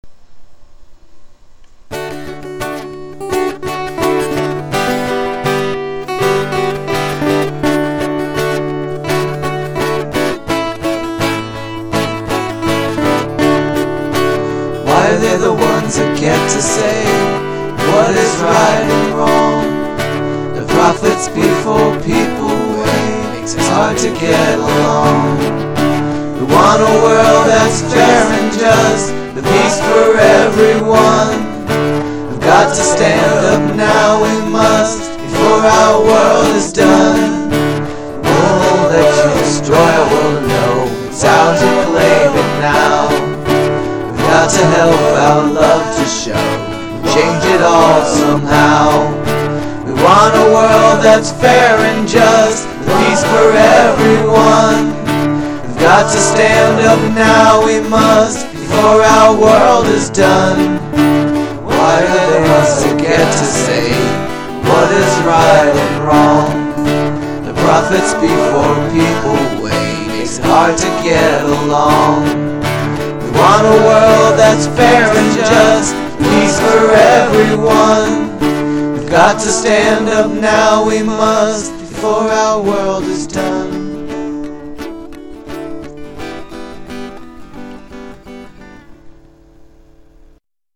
Download rough Demo MP3